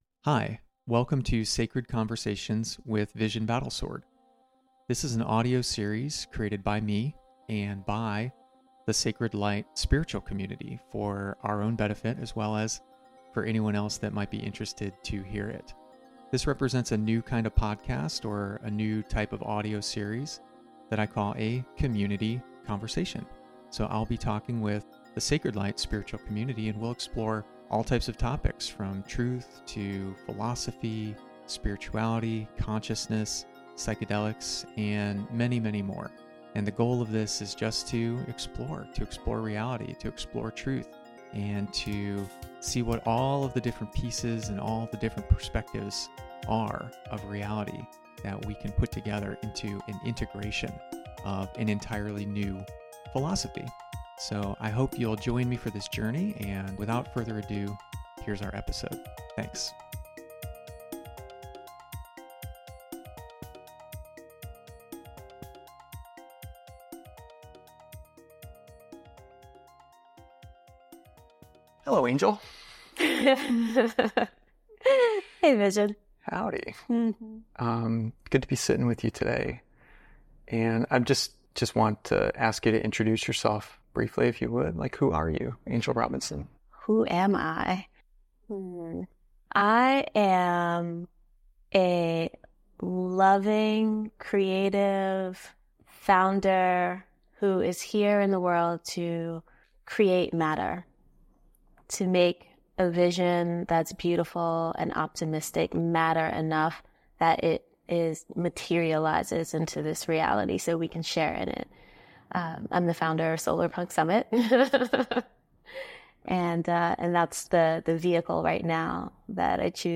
conversation03-manifestation.mp3